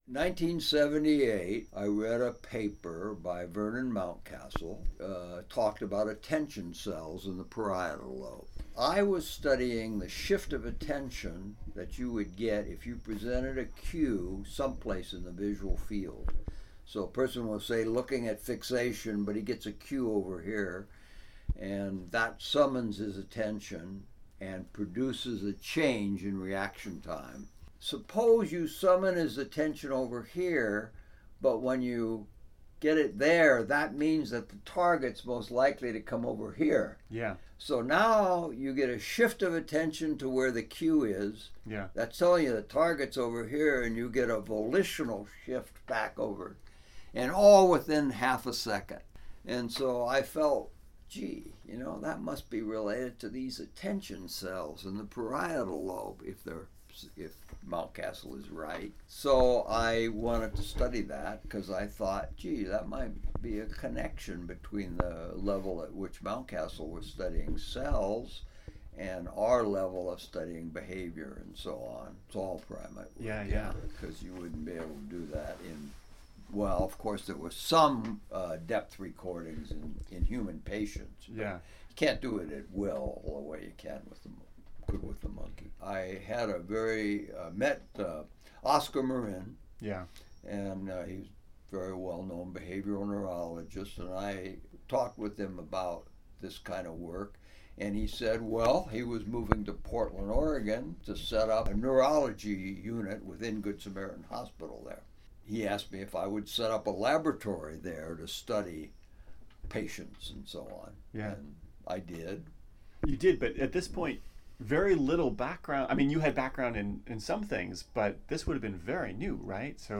In this excerpt, Dr. Posner describes how he got started on neuroimaging: